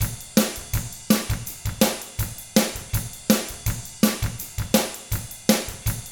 164ROCK T5-L.wav